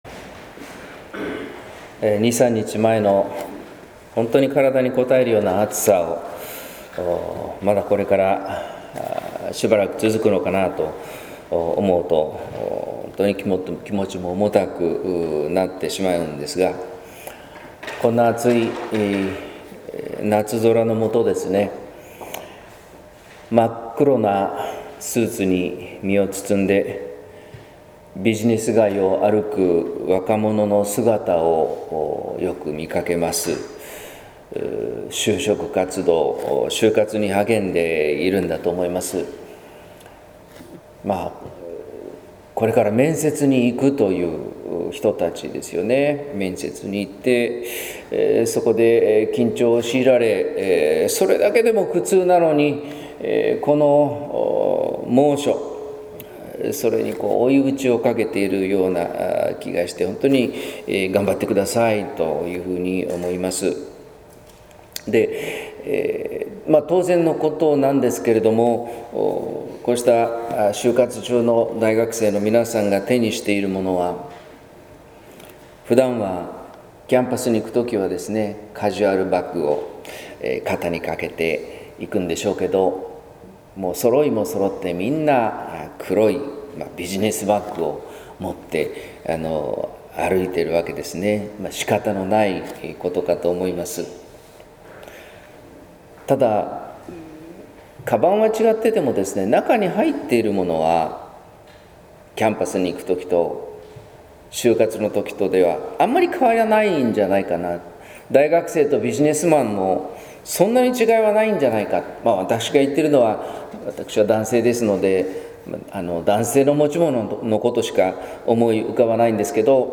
説教「お迎えするのが神の国」（音声版）